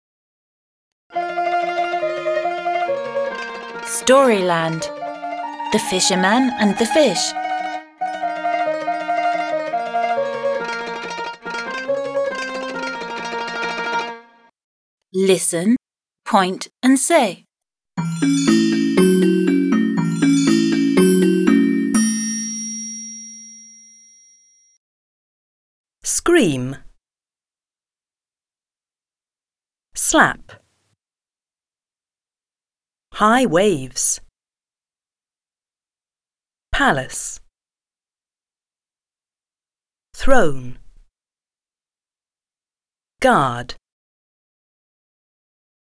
Прослушай слова, покажи соответствующие им картинки и повтори слова за диктором.
08-Vocabulary-p.-22.mp3